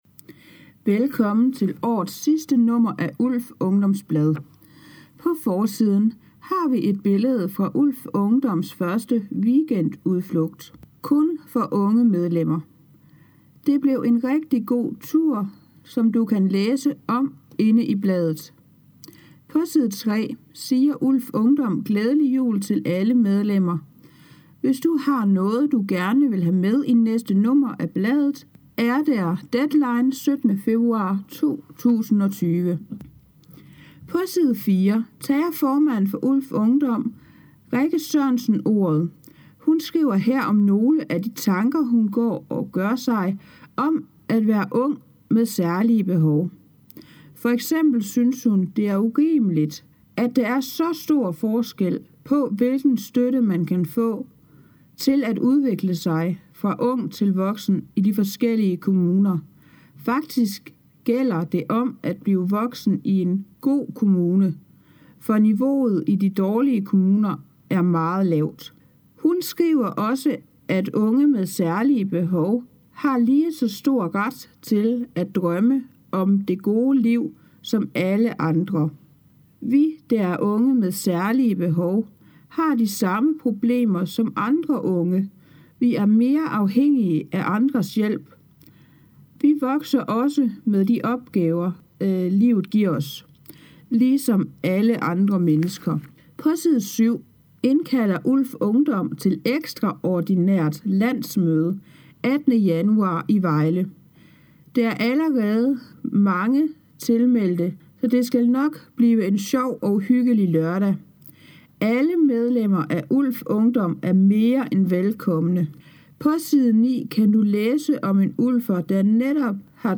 ULF Ungdom nr. 4 – 2019 – Er speaket hér: